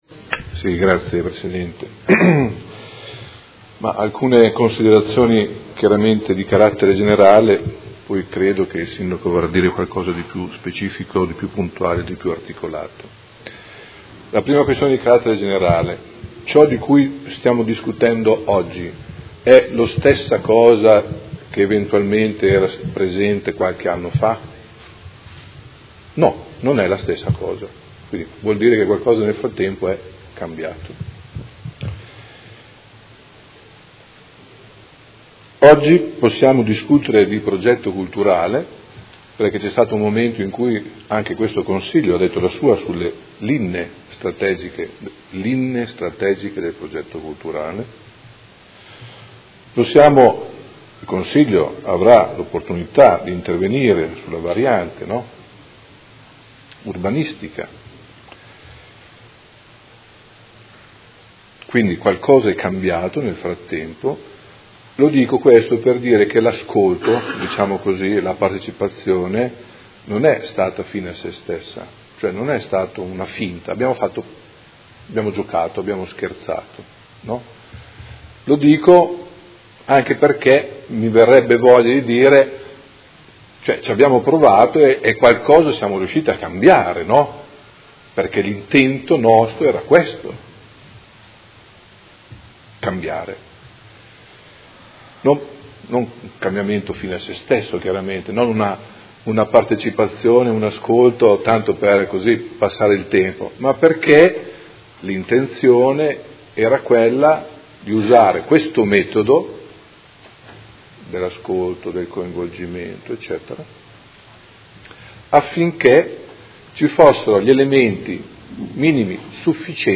Seduta del 19/04/2018. Dibattito su Ordine del Giorno presentato dal Movimento cinque Stelle avente per oggetto: Prosecuzione del percorso partecipato del Consiglio Comunale sul progetto del Sant’Agostino-Estense e Mozione presentata dai Consiglieri Poggi, Arletti, Lenzini, De Lillo, Forghieri, Venturelli, Bortolamasi, Liotti, Baracchi, Pacchioni e Di Padova (PD) e dal Consigliere Trande (Art.1 MDP/Per me Modena) avente per oggetto: Progetto Polo Culturale Sant’Agostino-Estense: soddisfazione per gli sviluppi del progetto e continuazione del percorso di condivisione